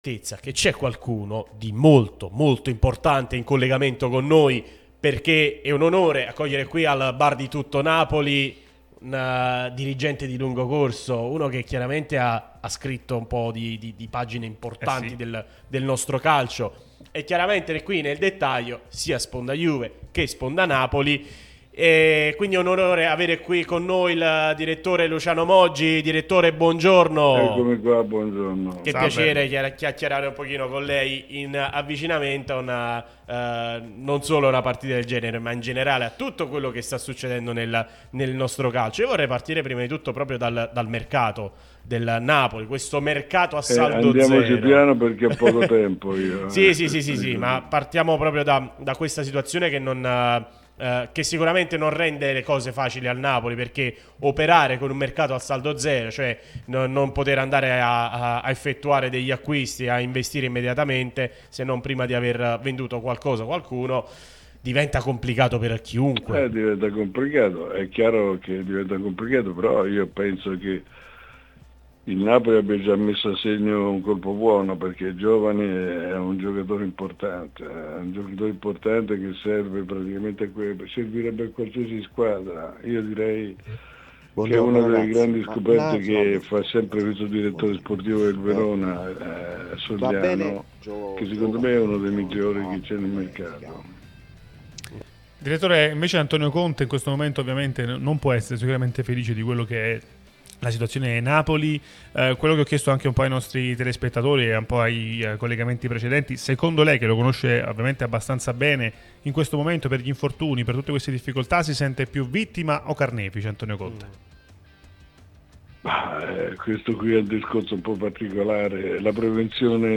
Luciano Moggi, ex dirigente di Napoli e Juventus, è intervenuto su Radio Tutto Napoli, prima radio tematica sul Napoli, che puoi ascoltare/vedere qui sul sito, in auto col DAB Campania o sulle app gratuite (scarica qui per Iphone o qui per Android).